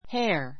hair 中 A1 héə r ヘ ア 名詞 複 hairs héə r z ヘ ア ズ （人の） 髪 かみ の毛 ; （人・動物の） 体毛, 毛 ✓ POINT 人・動物の体に生える「毛」をいうが, ふつうは人間の「髪の毛」を指す. grow one's hair long grow one's hair long 髪の毛を長く伸 の ばす He has gray hair.